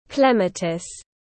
Hoa ông lão tiếng anh gọi là clematis, phiên âm tiếng anh đọc là /ˈklem.ə.tɪs/.
Clematis /ˈklem.ə.tɪs/